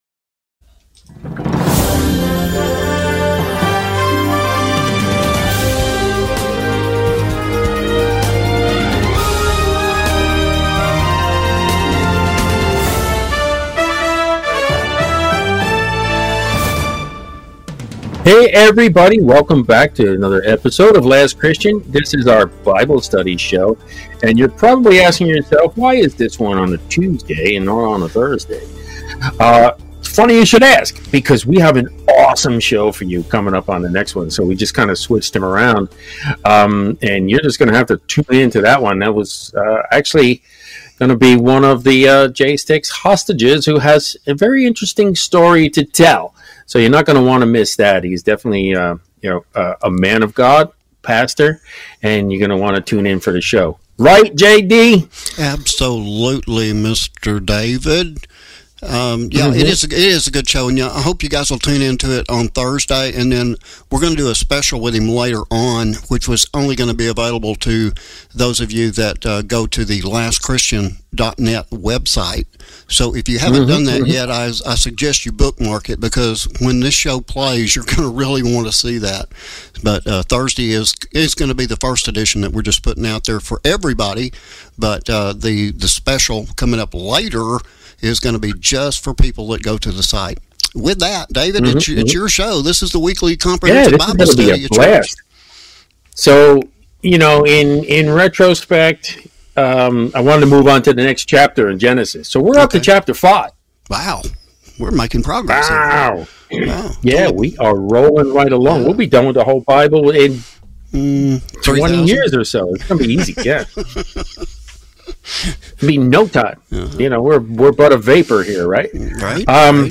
Weekly Comprehensive Bible Study With Bible Teacher & Scholar